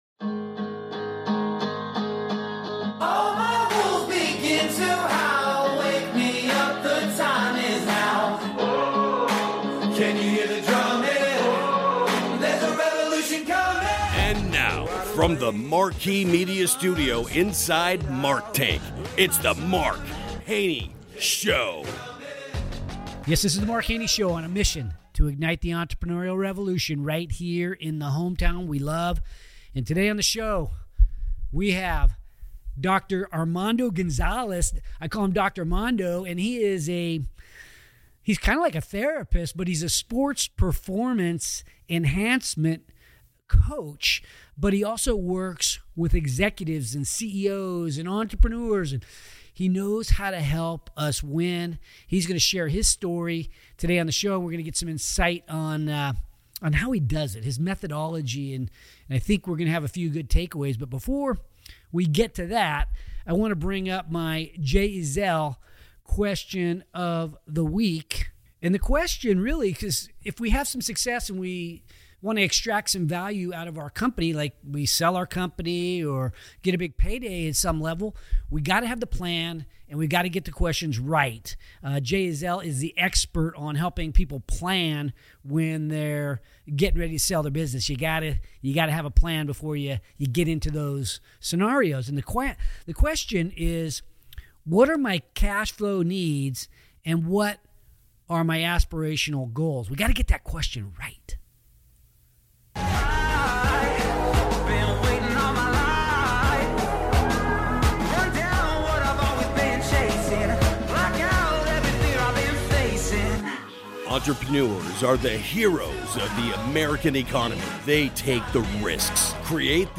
In this riveting conversation, we’ll explore the transformative power of emotional connection, music, therapeutic relationships, and even the direction of your gaze.